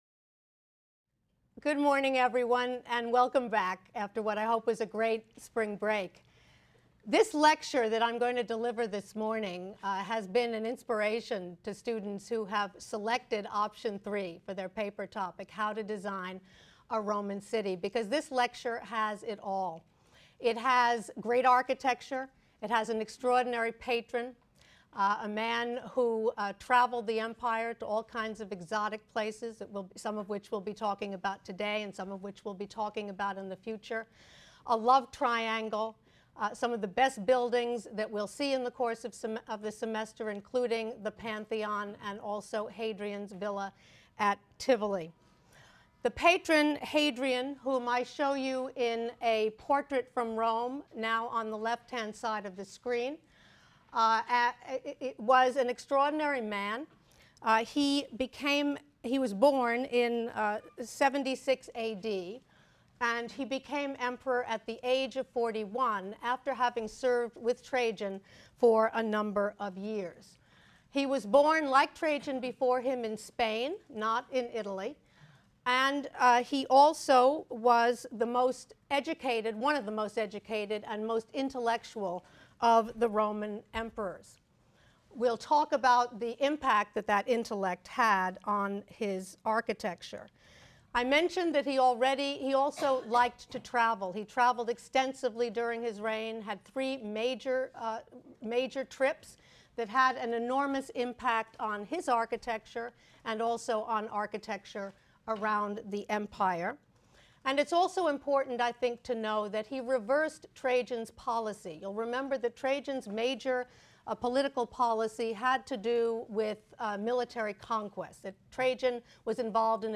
HSAR 252 - Lecture 15 - Rome and a Villa: Hadrian’s Pantheon and Tivoli Retreat | Open Yale Courses